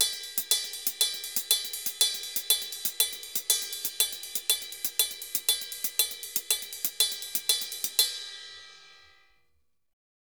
Ride_Salsa 120_1.wav